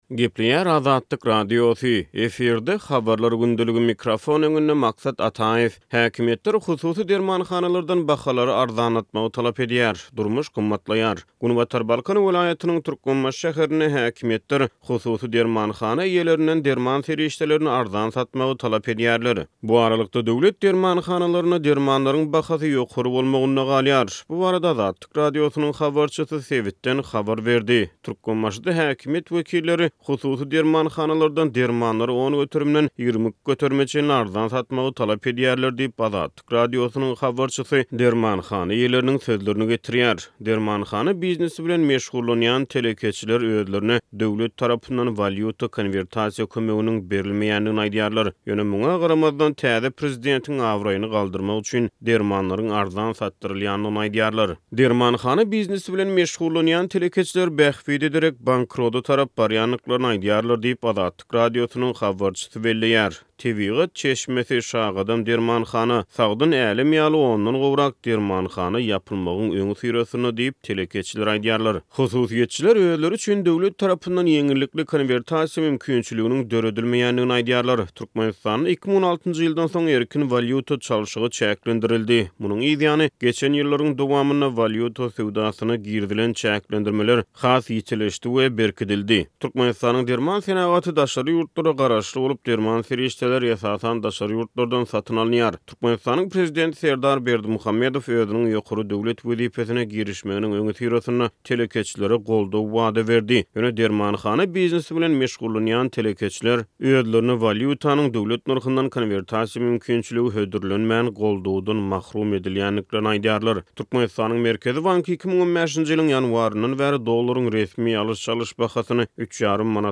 Bu barada Azatlyk Radiosynyň habarçysy sebitden habar berdi.